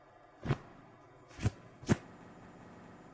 Tiếng Phủi Áo Quần… (Ngắn)
Thể loại: Tiếng động
Description: Tiếng phủi, vỗ, giũ, đập nhẹ lên áo quần vang “bộp… phạch” gọn gàng, dứt khoát. Âm thanh vải rung, sột soạt... mang theo cảm giác sạch sẽ và chỉnh tề. Trong cảnh phim hoặc video, hiệu ứng này được ghi rõ nét để tái hiện trọn vẹn khoảnh khắc ngắn nhưng sống động, tạo điểm nhấn âm thanh tinh tế cho từng phân cảnh.
tieng-phui-ao-quan-ngan-www_tiengdong_com.mp3